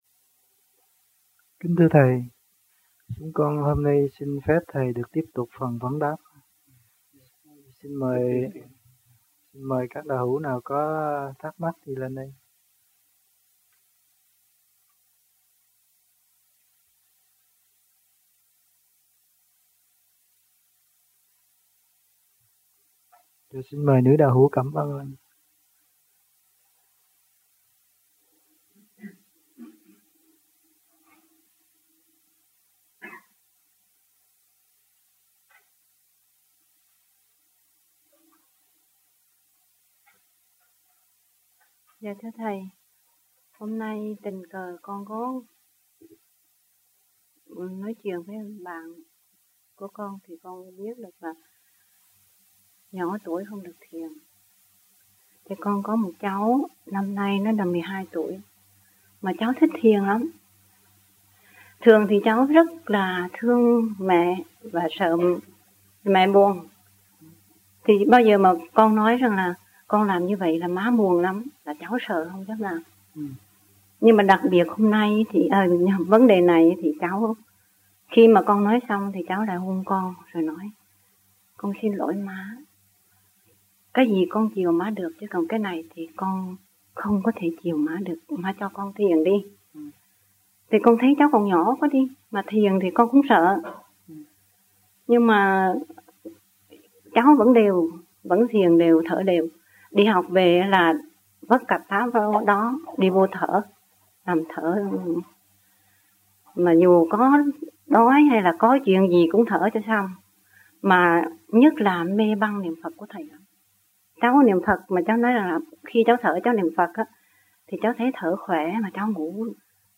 1986 Đàm Đạo
1986-09-13 - VẤN ĐẠO 01 - KHOÁ 1 - THIỀN VIỆN QUY THỨC